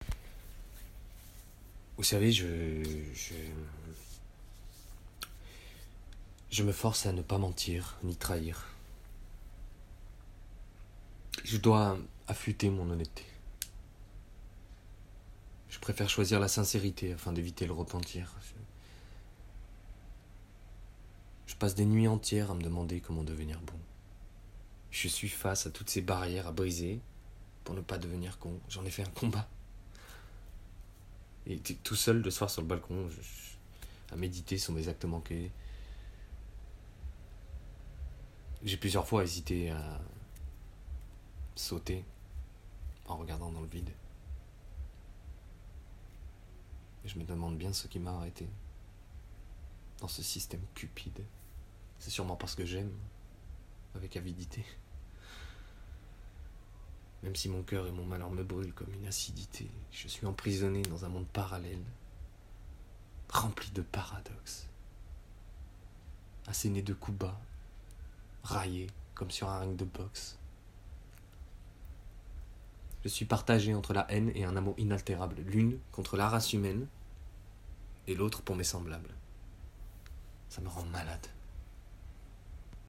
Démo Voix 2